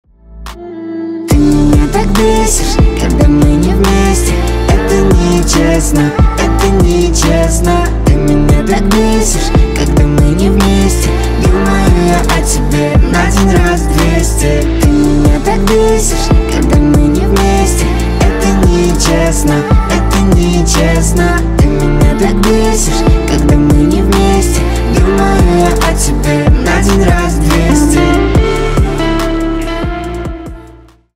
Поп Музыка
грустные
спокойные